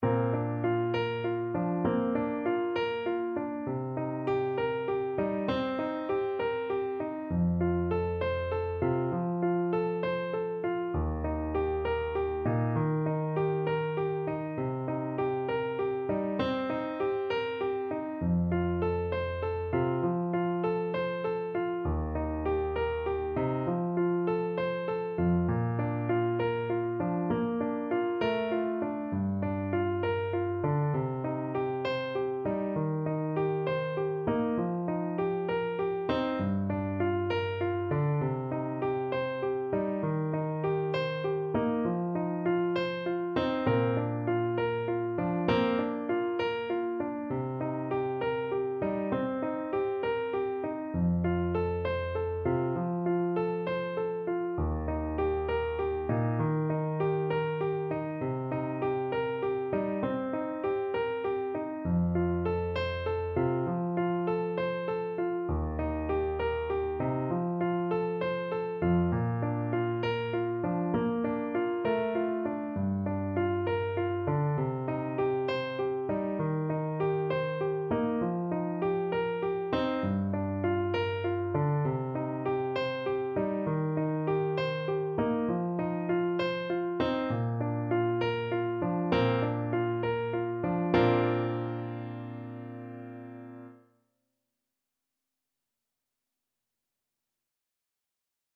Classical (View more Classical Trombone Music)